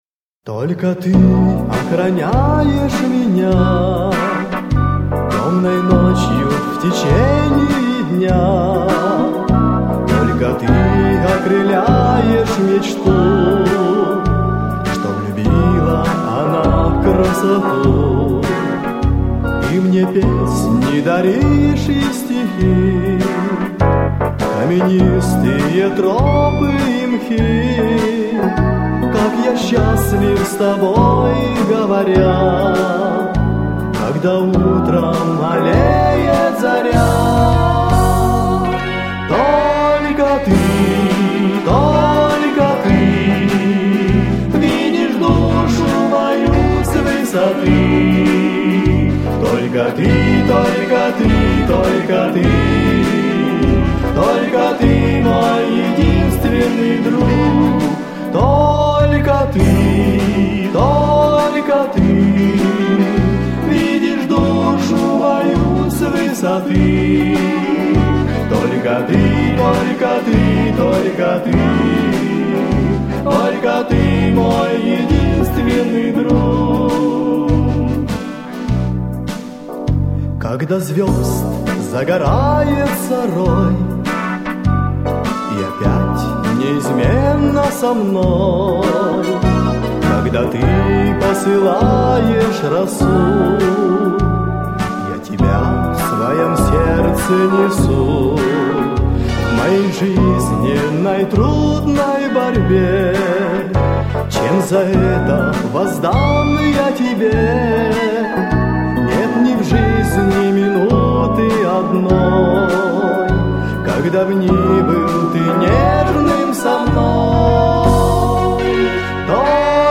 295 просмотров 604 прослушивания 47 скачиваний BPM: 75